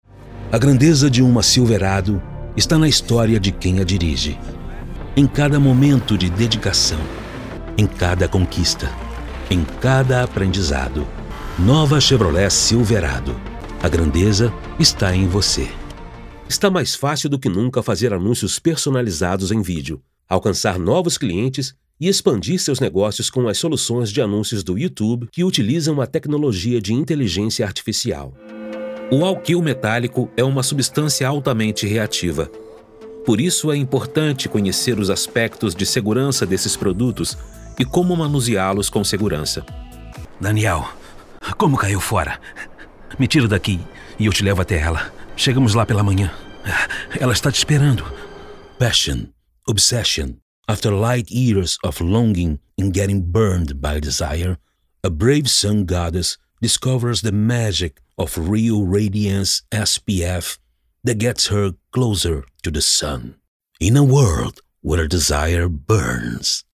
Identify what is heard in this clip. Sprechprobe: Sonstiges (Muttersprache):